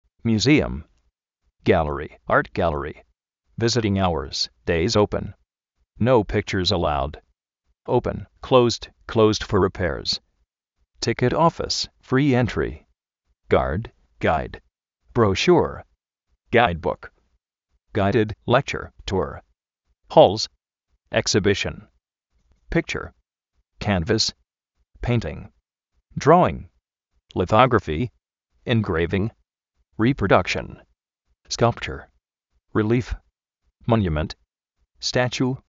miusíum
gáleri, á:rt gáleri
vísitin áurs, déis óupen